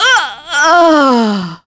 Maya-Vox_Dead1.wav